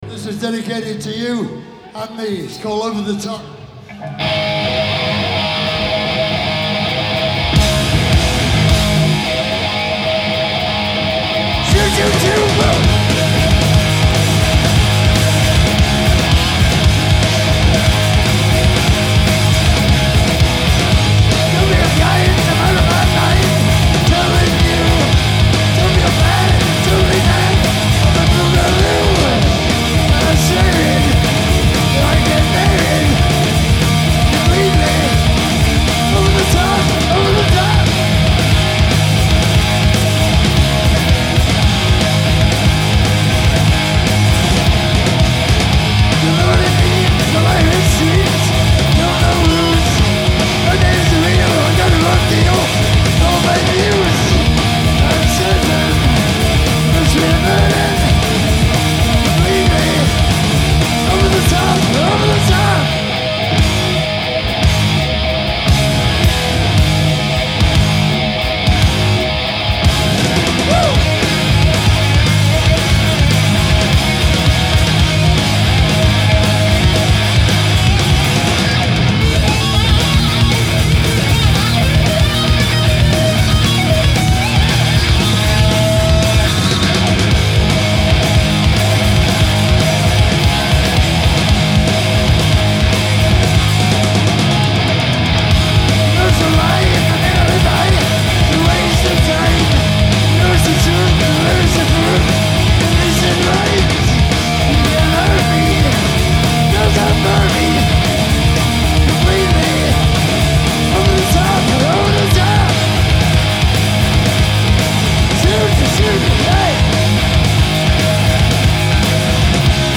Hi-Res Stereo
Genre : Rock